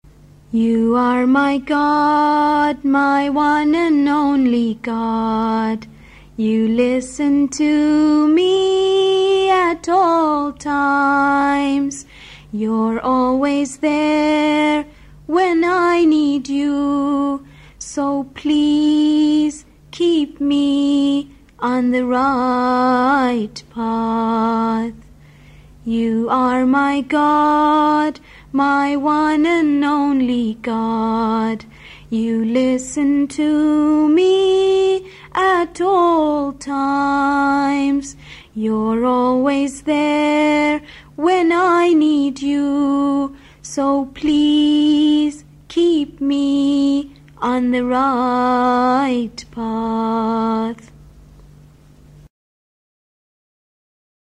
Part of a series of Muslim rhymes for children.